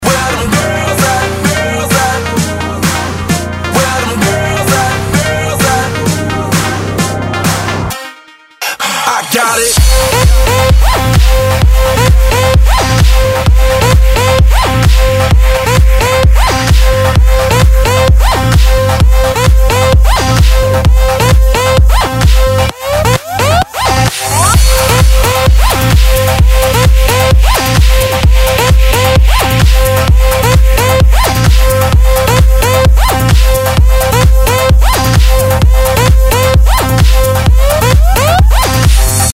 из Клубные